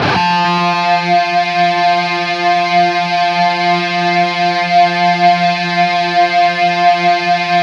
LEAD F 2 LP.wav